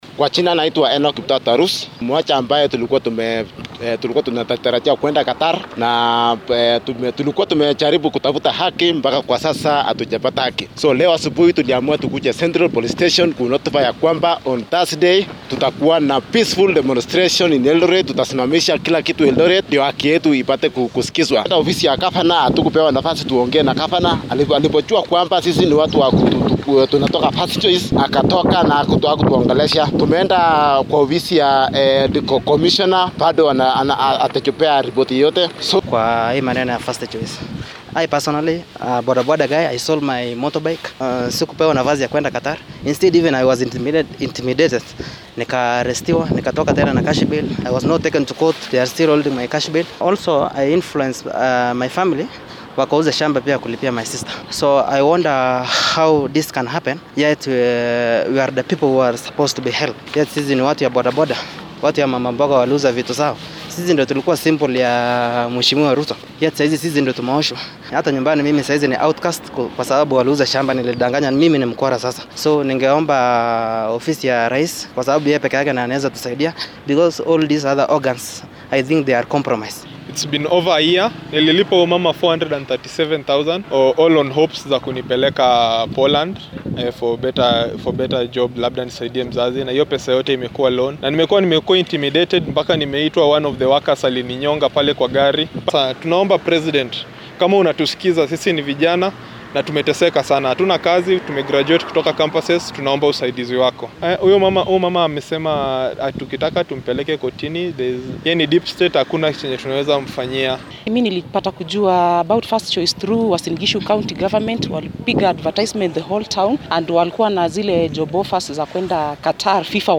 JOB-SEEKRS-SOUND-BITE-.mp3